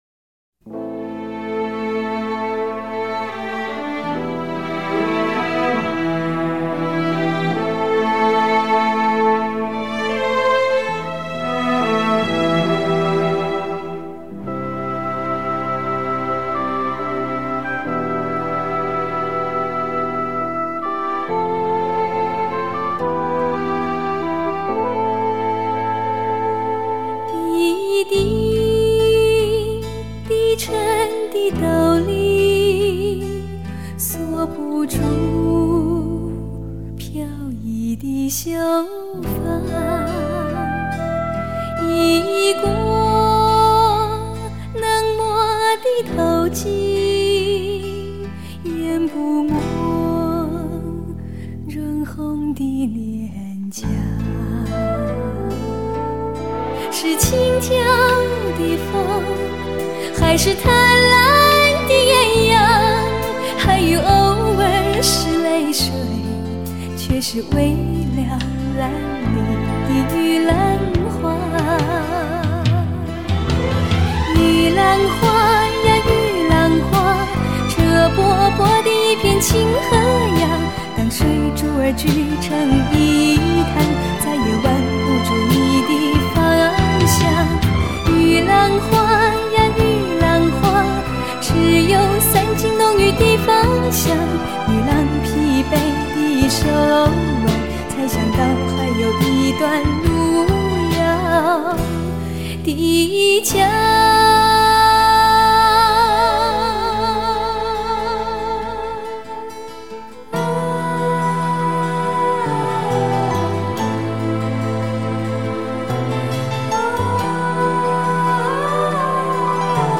清新淡雅的气质 绽放古典的优雅风华
甜美圆润的音色 席卷传统的婉约温柔